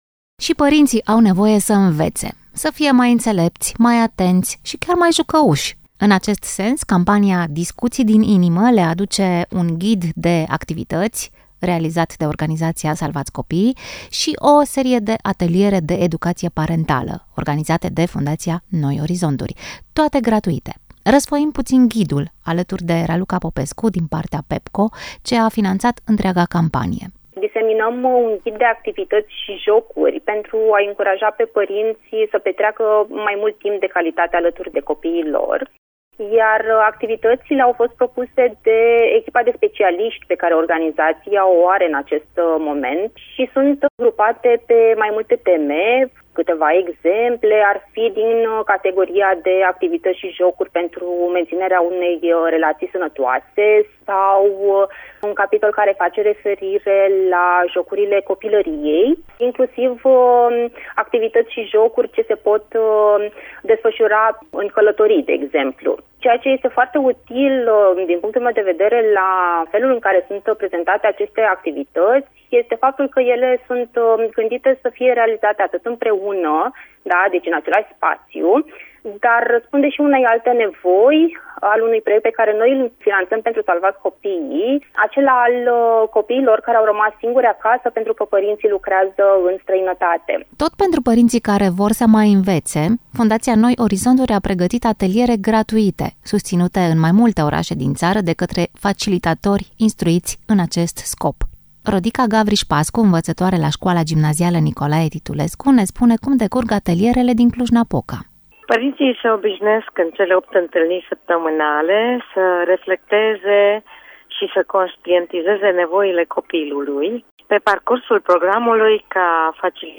Mai multe despre ghid și ateliere aflăm din interviu: